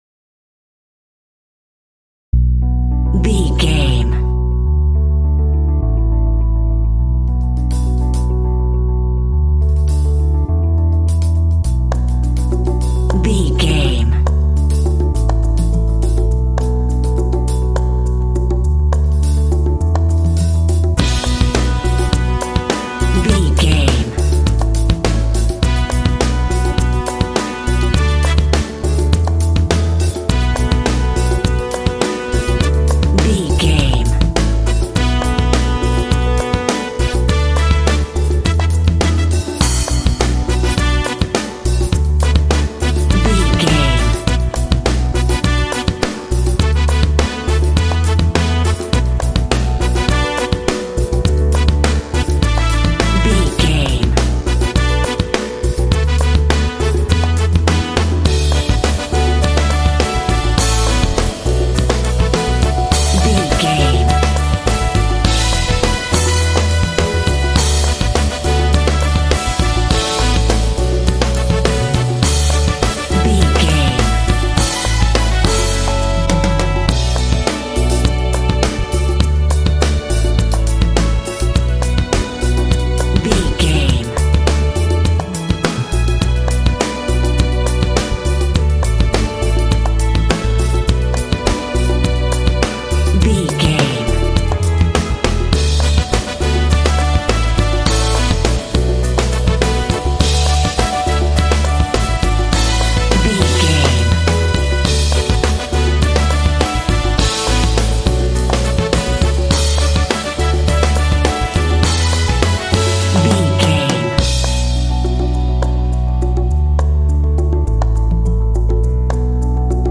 Aeolian/Minor
groovy
repetitive
bass guitar
drums
piano
brass
contemporary underscore